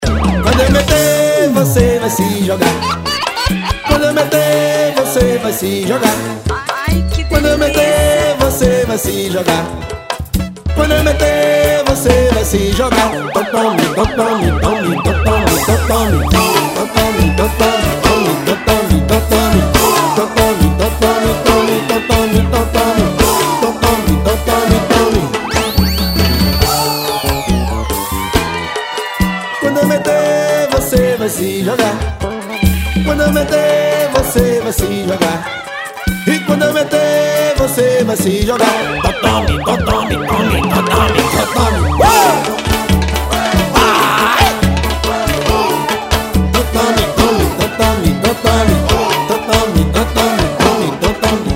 Alguns sucessos de Pagodes Baiano.